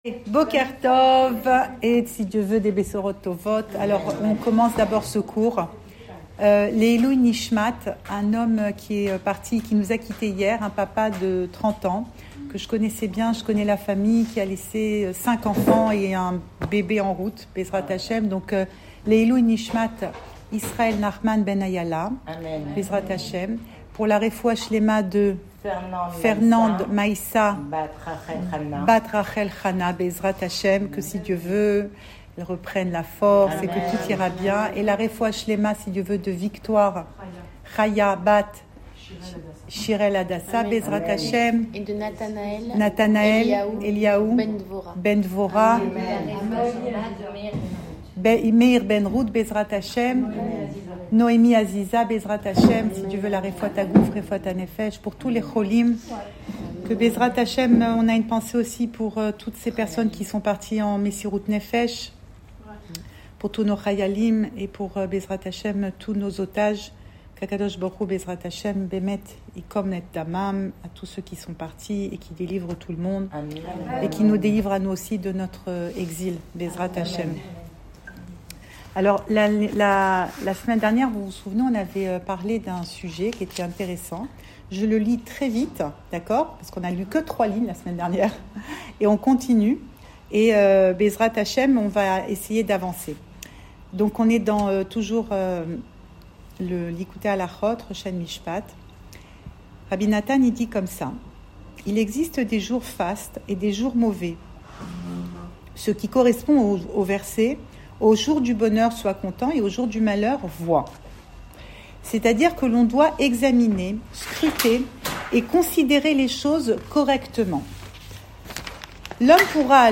Cours audio Emouna Le coin des femmes Le fil de l'info Pensée Breslev - 30 avril 2025 1 mai 2025 Des jours meilleurs. Enregistré à Tel Aviv